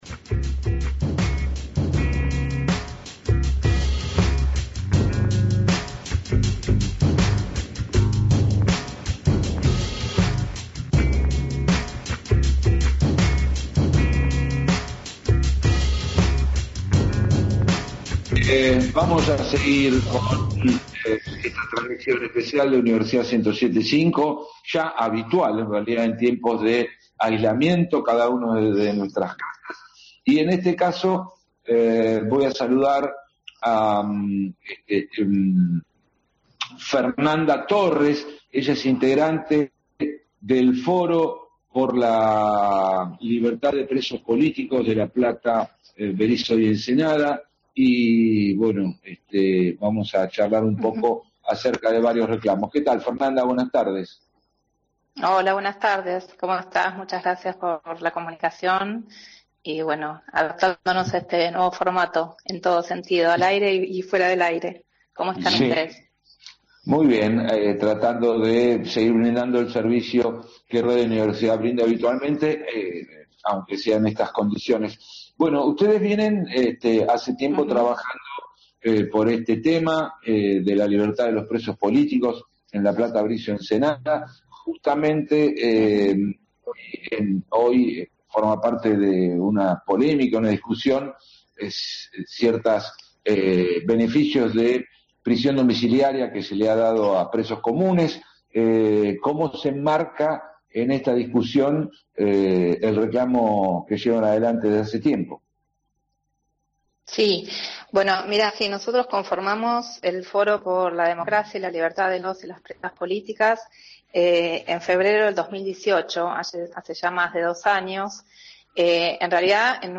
La entrevista completa